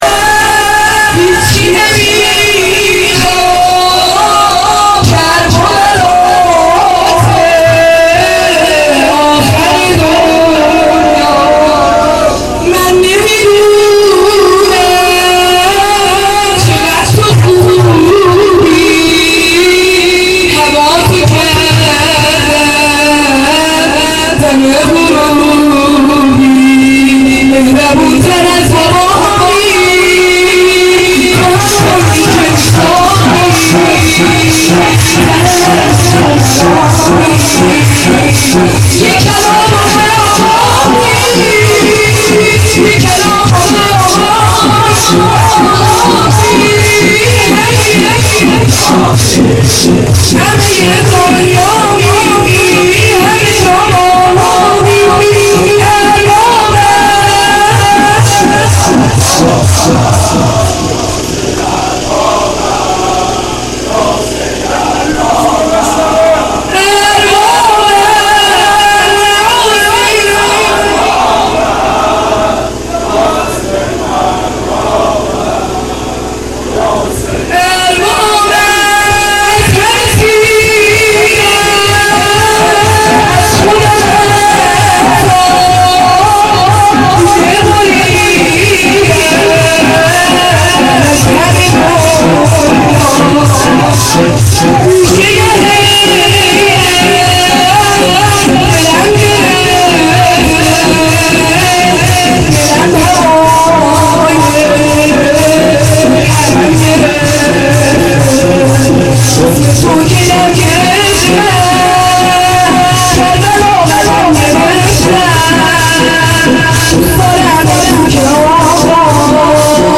شور | من تو رو دارم